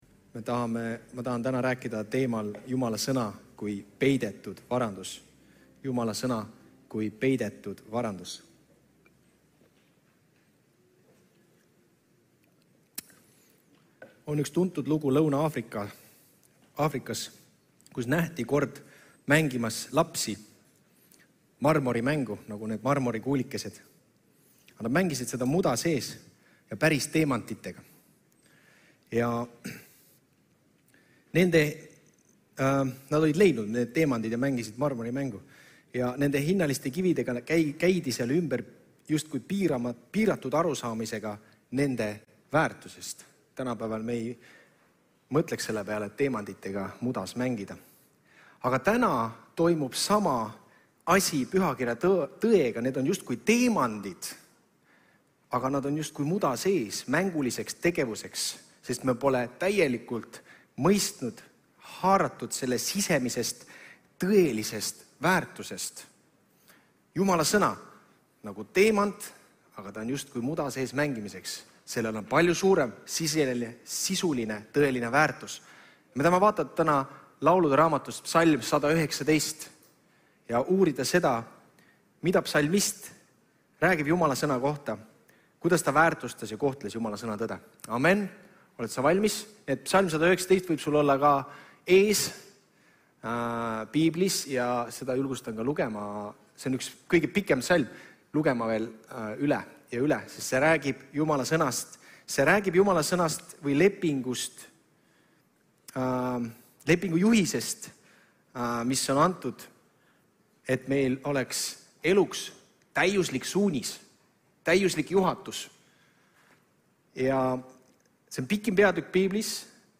06.07.2025 Jutlus - Jumala sõna kui peidetud varandus